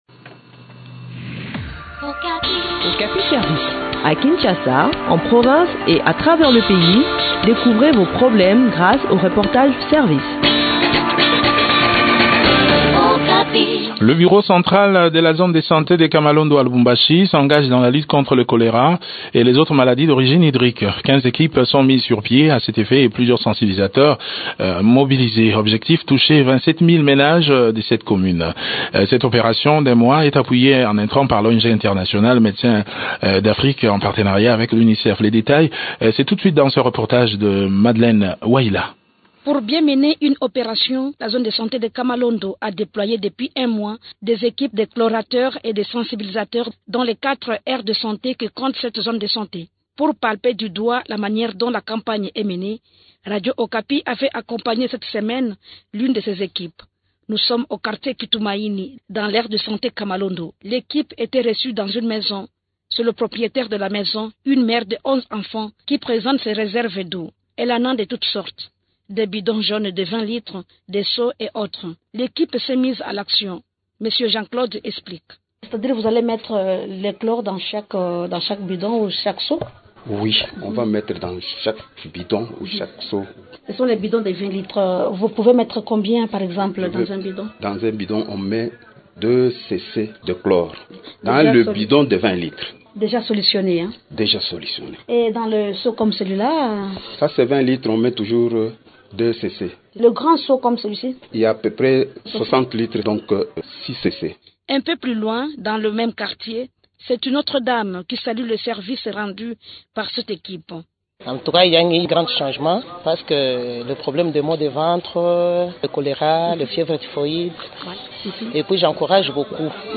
Le point sur le déroulement de cette campagne dans cet entretien